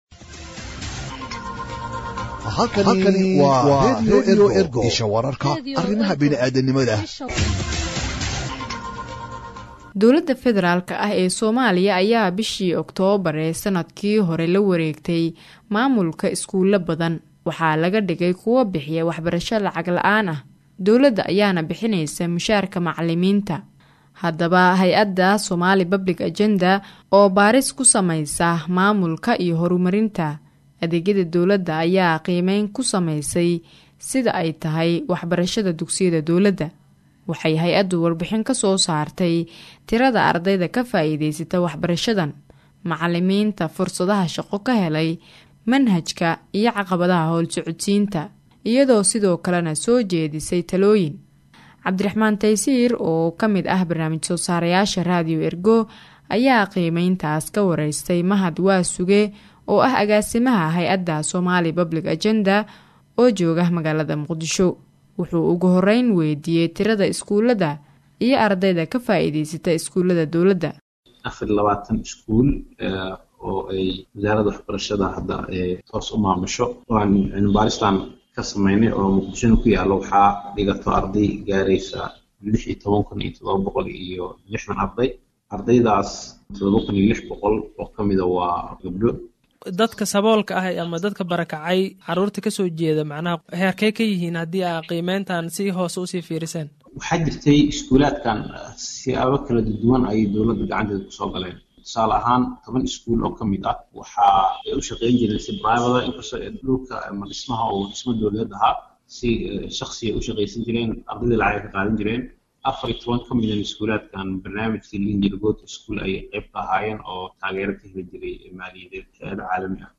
Wareysiga-waxbarashada.mp3